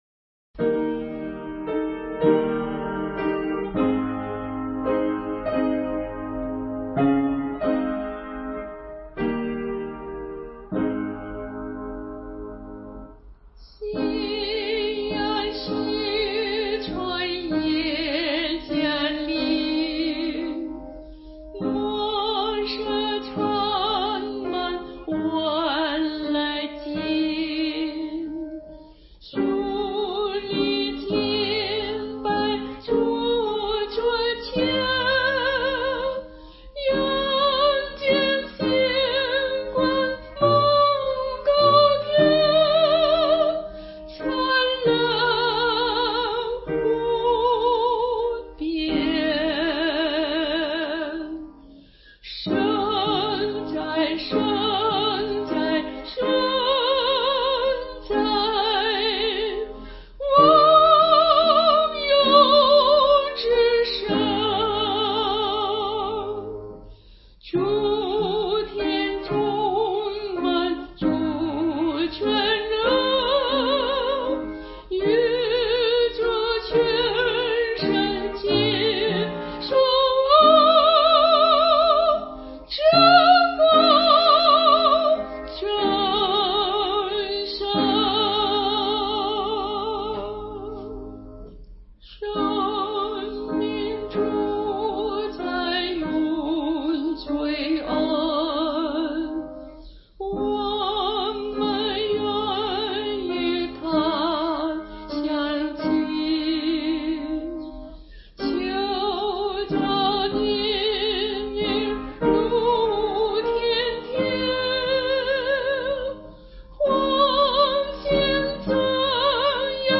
伴奏
这是一首十分优美的晚祷曲。旋律平稳而庄重，把人带进从“夕阳西沉白昼尽”至“晨曦不灭常照耀”的美景之中，得以瞻仰上帝的伟大和慈爱，达到人，神和大自然合而为一的境界。